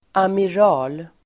Ladda ner uttalet
Uttal: [amir'a:l]